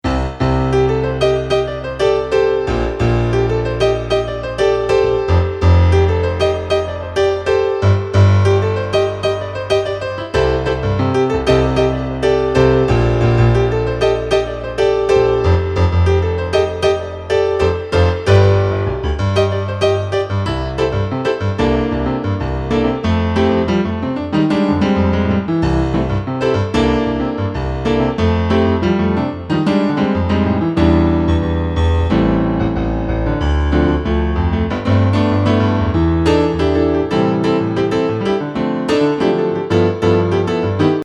(instrumental track)